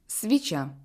Deux consonnes sont toujours molles: Ч, Щ